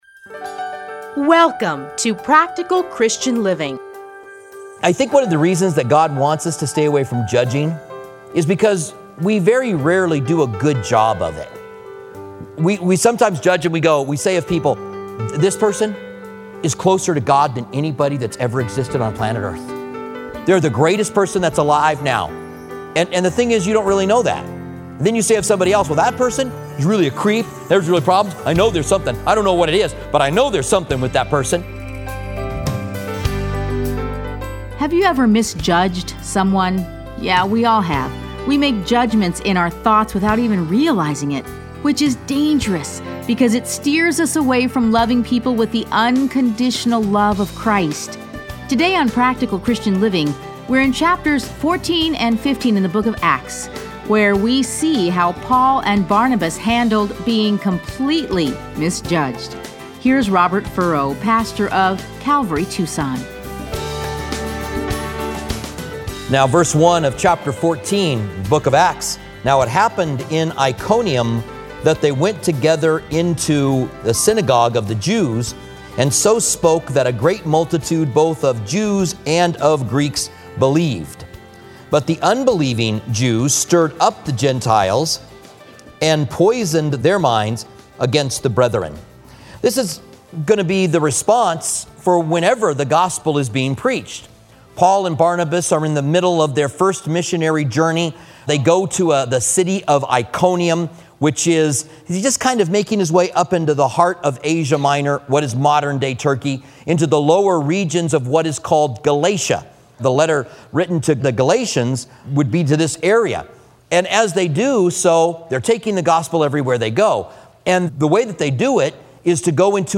Listen to a teaching from Acts 14.